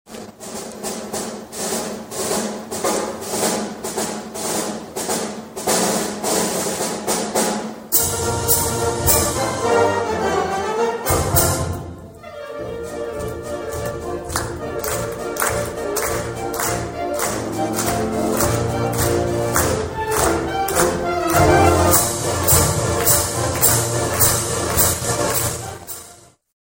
Emporia Municipal Band serenades Granada Theatre audience with annual Christmas concert
With great fanfare, the Emporia Municipal Band played its annual Christmas concert Sunday.
From the Radetzky March, heard here, to favorites like Sleigh Ride, the band entertained a big crowd at the Emporia Granada Theatre for about an hour.